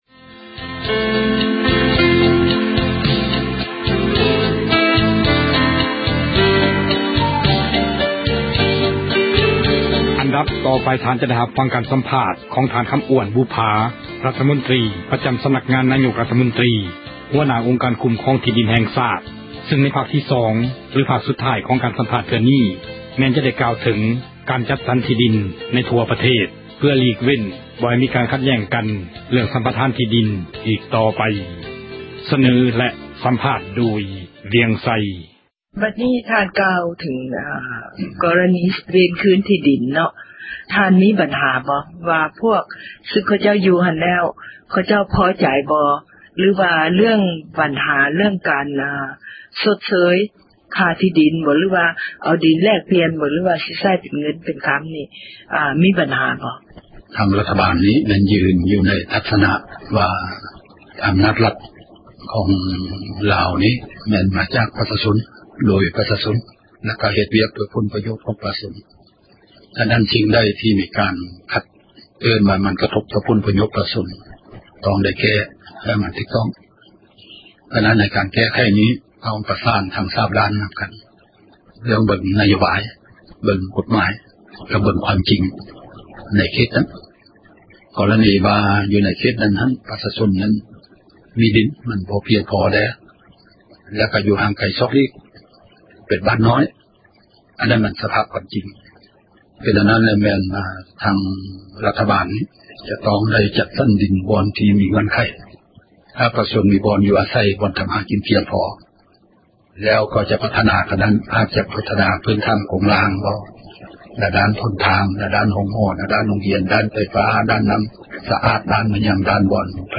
ເຊິນທ່ານຟັງ ການໃຫ້ສັມພາດ ຂອງທ່ານ ຄໍາອວ້ນ ບຸບຜາ ຣັຖມົນຕຣີ ປະຈໍາສໍານັກງານ ນາຍົກຣັຖມົນຕຣີ ຫົວໜ້າອົງການ ຄຸ້ມຄອງທີ່ດີນ ແຫ່ງຊາຕ ຊຶ່ງໃນພາກທີ່ສອງ ຫຼືພາກສຸດທ້າຍ ຂອງການສັມພາດ ເທື່ອນີ້ ແມ່ນຈະໄດ້ກ່າວເຖິງ ການຈັດສັນ ທີ່ດີນ ໃນທົ່ວປະເທດ ເພື່ອຫລີກເວັ້ນ ບໍ່ໃຫ້ມີການ ຂັດແຍ້ງກັນ ໃນເຣື້ອງ ສັມປະທານ ທີ່ດີນ ອີກຕໍ່ໄປ.